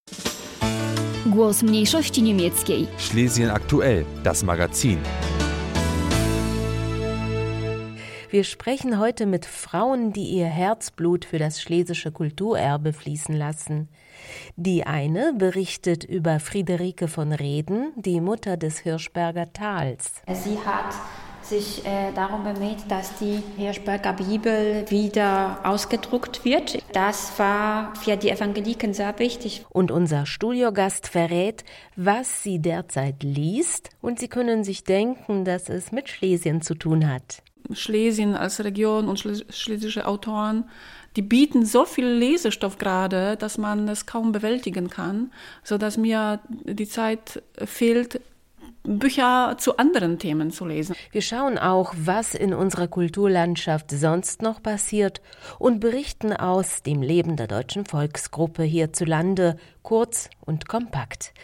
Wir sprechen heute mit Frauen, die ihr Herzblut für das schlesische Kulturerbe fließen lassen.